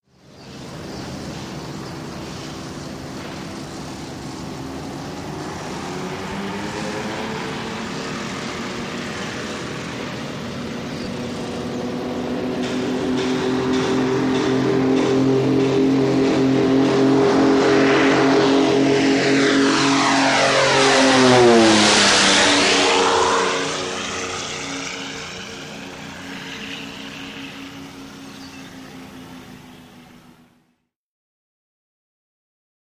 WW2 Fighters|P-38 | Sneak On The Lot
Airplane P-38 Takeoff Pass Right To Left Close Perspective